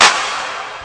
Clap (5).wav